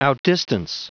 Prononciation du mot outdistance en anglais (fichier audio)